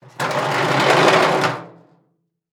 Garbage Can Drag Sound
household
Garbage Can Drag